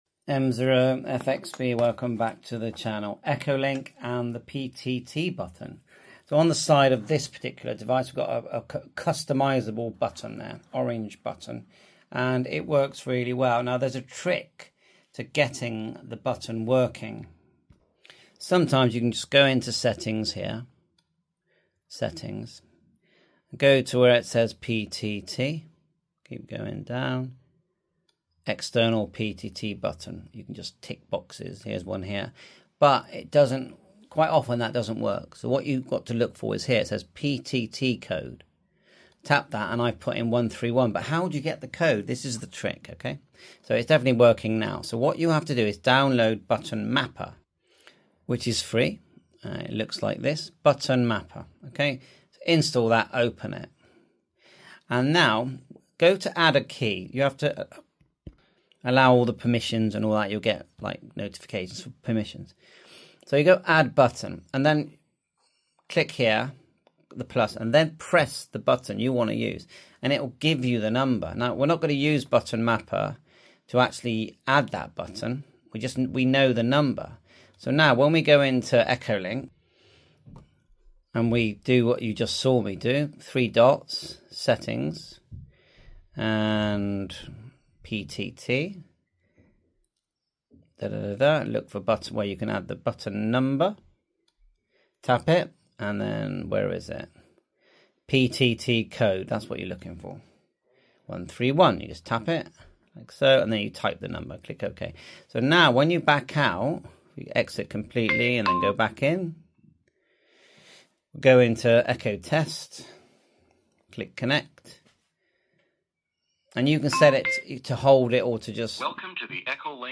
PTT Buttons Ham Radio sound effects free download
PTT Buttons - Ham Radio Mp3 Sound Effect